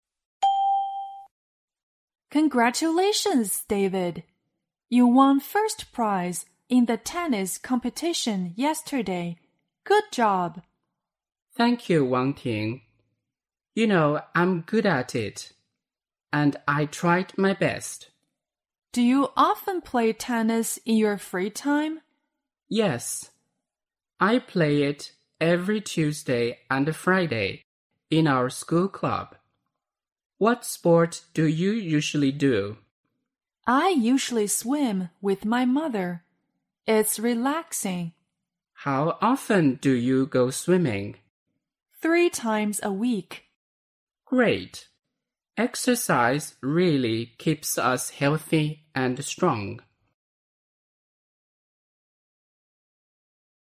2023年中考(陕西卷)英语听力真题 长对话 - 中考英语 - 福珀网 - 让学英语更简单